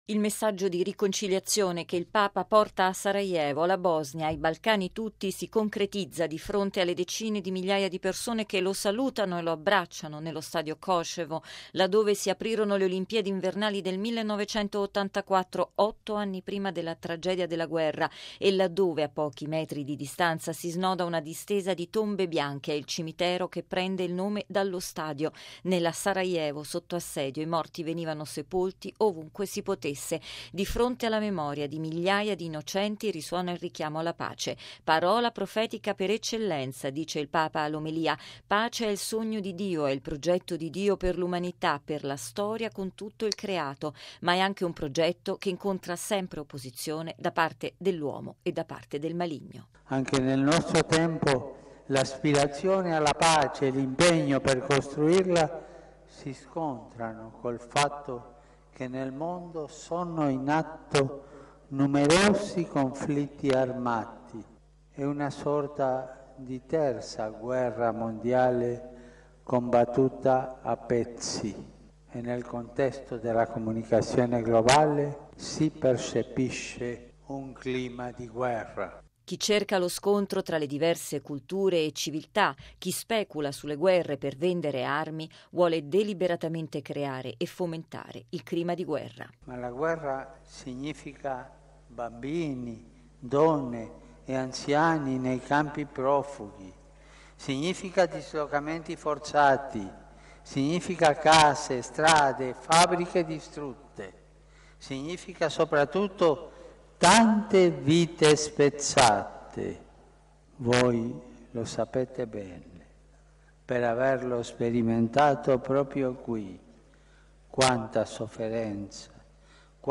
Sono ben oltre 60 mila i fedeli davanti ai quali il Papa celebra la Messa, tra loro, in un apposito spazio, mutilati e feriti del conflitto degli anni ’90.